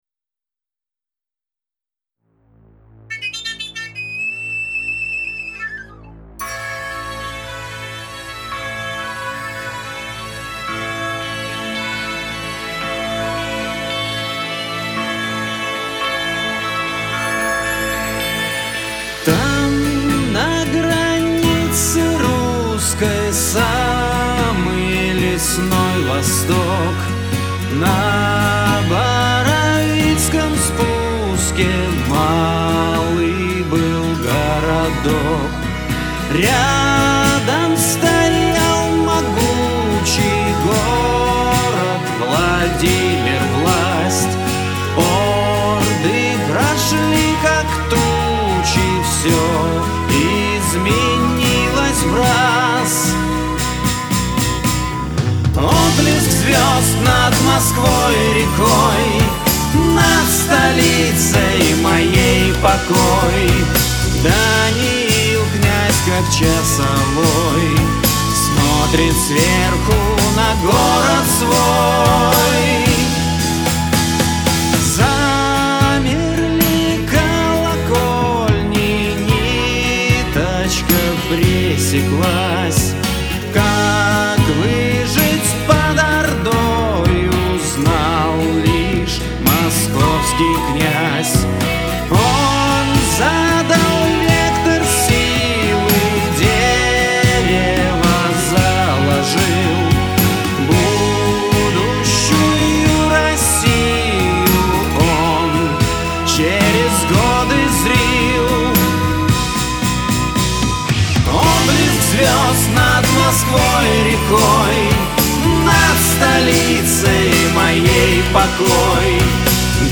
Фолк Рок